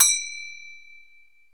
Index of /90_sSampleCDs/Roland L-CD701/PRC_Asian 2/PRC_Gongs